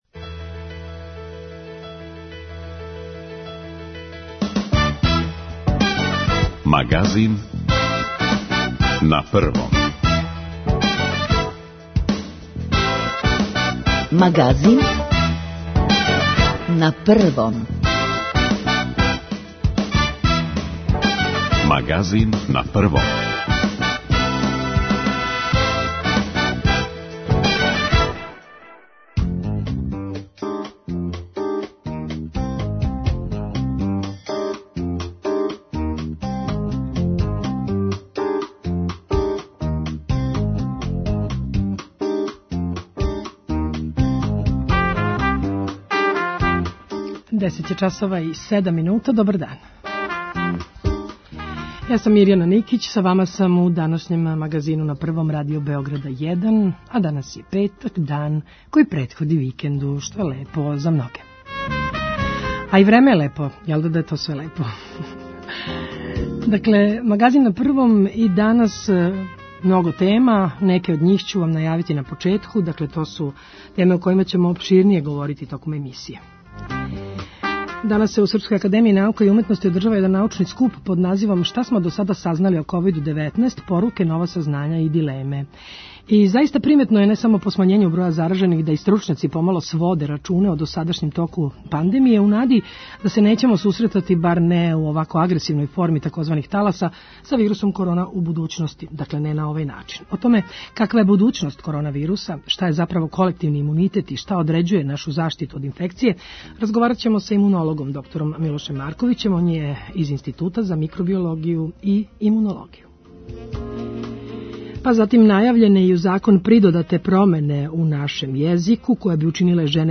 Извор: Радио Београд 1